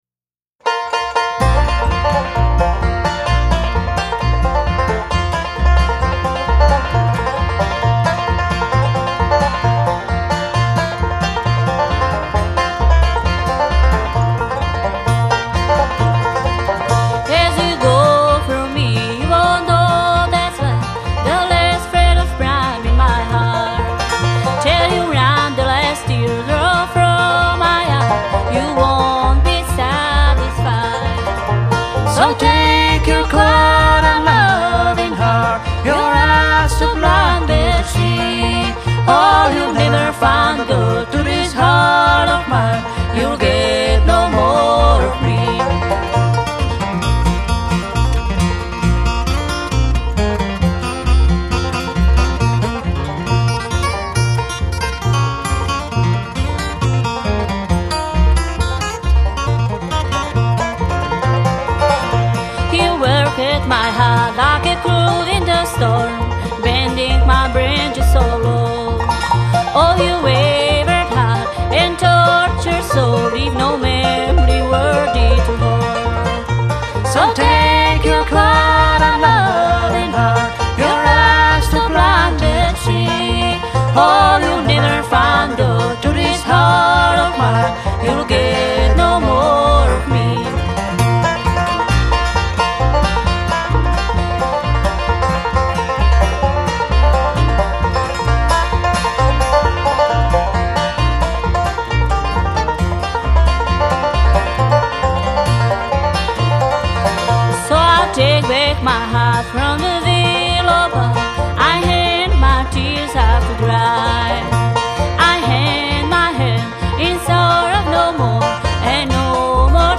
Live DEMO